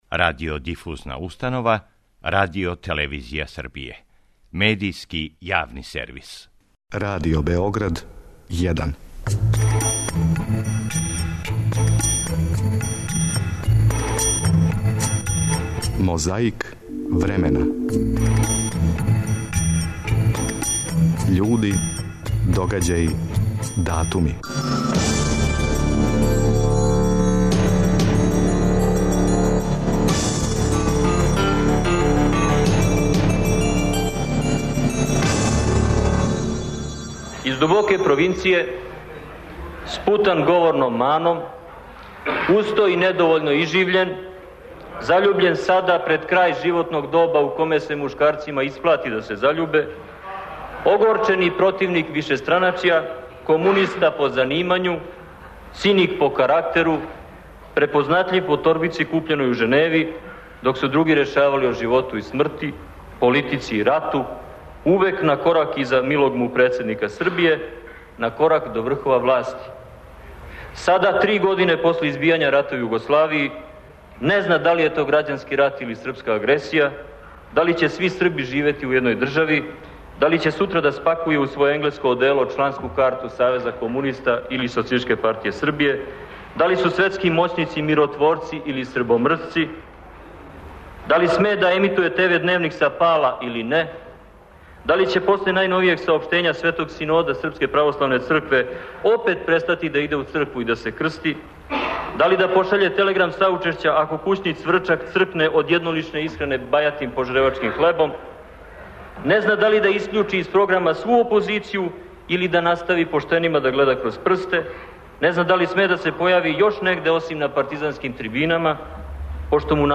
Микрофони су забележили заклетву, инаугурациони говор, химну...